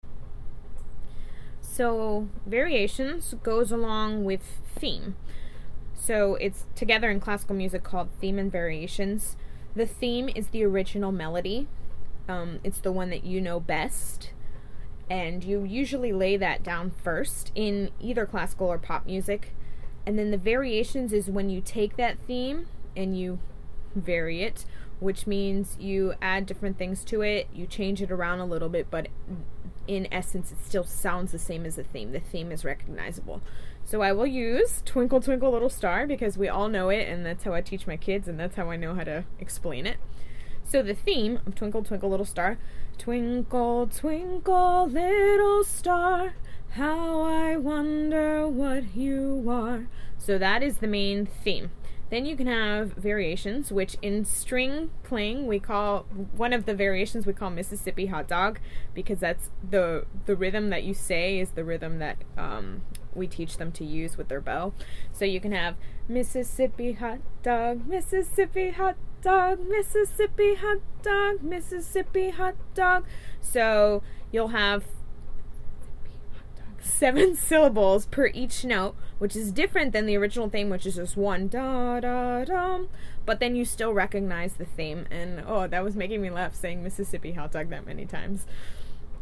Theme – The original melody of a song/piece.
Variations – A variation is an alteration of the theme, but the theme is still recognizable within the variation.
mekdost-theme-and-variations.mp3